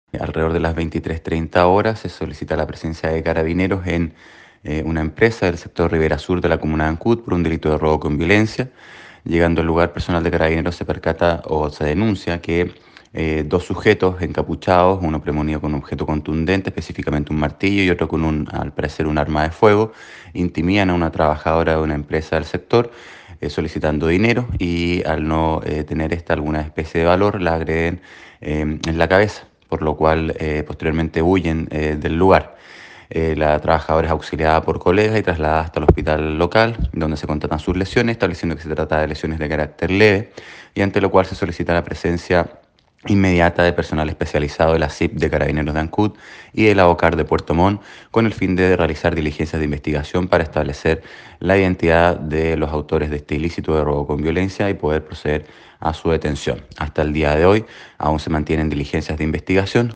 Acerca de este violento delito de robo que se produjo cerca de la medianoche del martes, el fiscal Luis Barría, de la fiscalía local de Ancud, manifestó que los delincuentes actuaron amedrentando al personal, premunidos de una presunta arma de fuego y con un objeto contundente, requerían que se les hiciera entrega de la recaudación del día.
01-FISCAL-ROBO-CON-VIOLENCIA-ANCUD.mp3